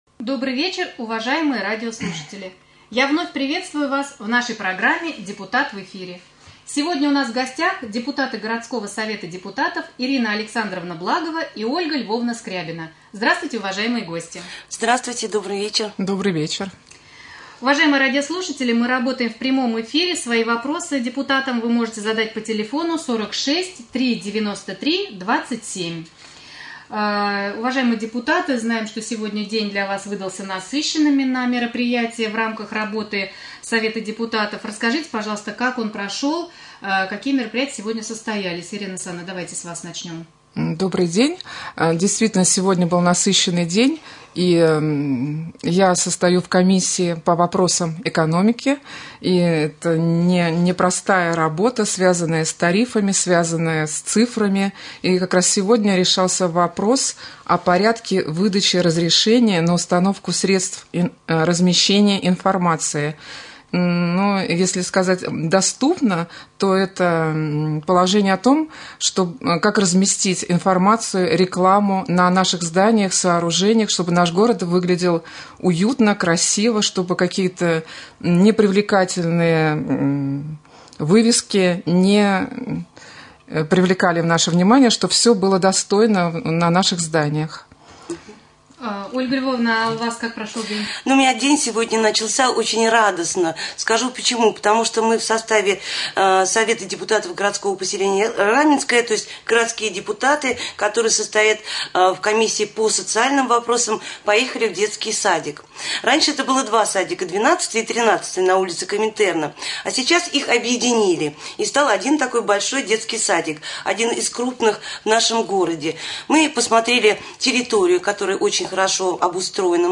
Прямой эфир с депутатами Совета депутатов городского поселения Раменское Ольгой Львовной Скрябиной и Ириной Александровной Благовой.